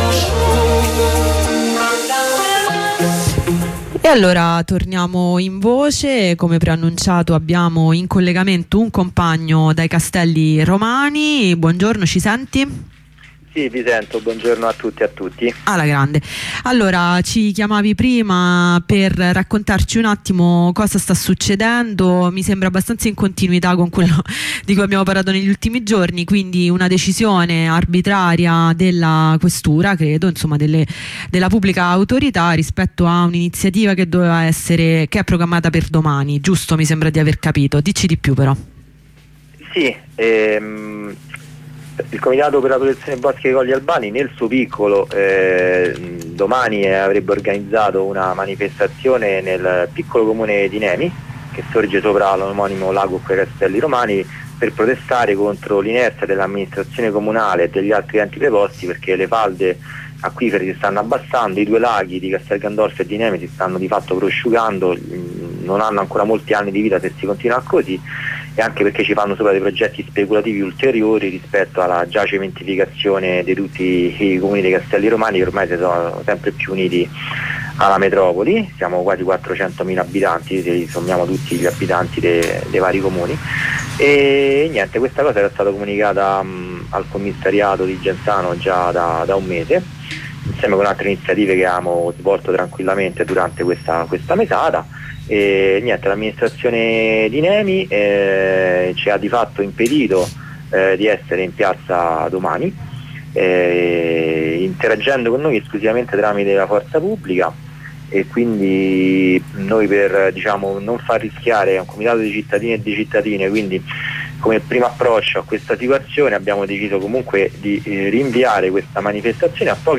Rassegna stampa 5 ottobre 2024
Trovate anche una corrispondenza con un compagno dei Castelli Romani che ci parla di un'iniziativa programmata per domani a Nemi e vietata dalla questura e rilancia la mobilitazione del 12 ottobre ad Albano contro il progetto di inceneritore.